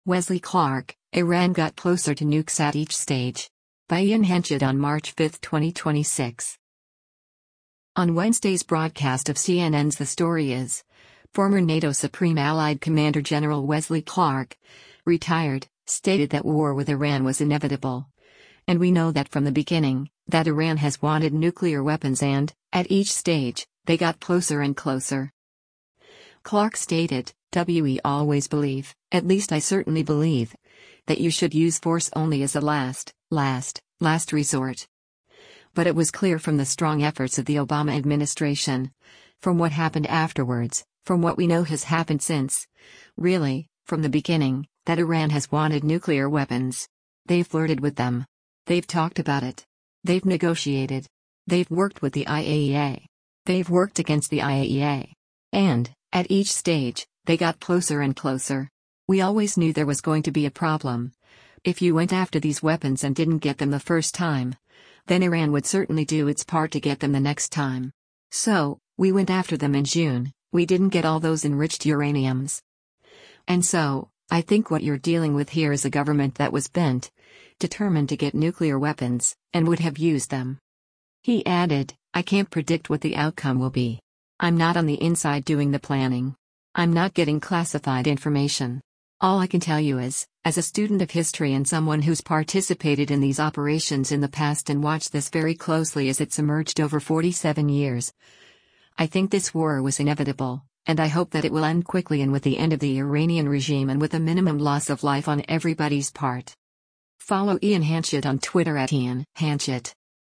On Wednesday’s broadcast of CNN’s “The Story Is,” former NATO Supreme Allied Commander Gen. Wesley Clark (Ret.) stated that war with Iran “was inevitable,” and we know that “from the beginning, that Iran has wanted nuclear weapons” “And, at each stage, they got closer and closer.”